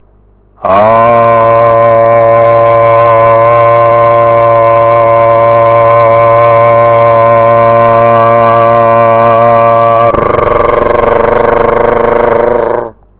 Mantram: AAAAAAARRRRRRRR
Nota: (FA)